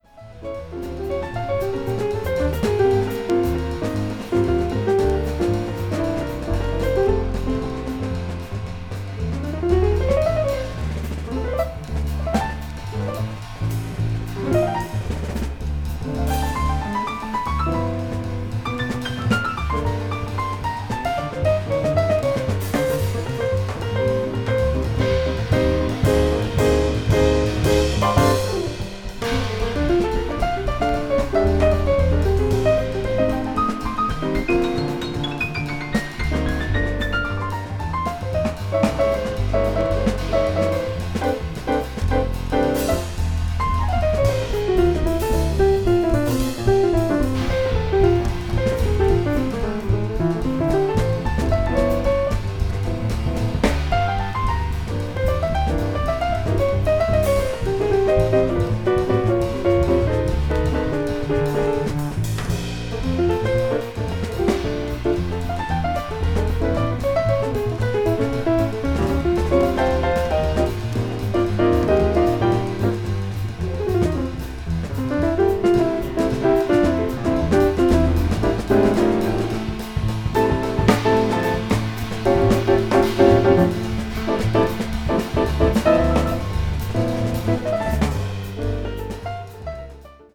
contemporary jazz   hard bop   piano trio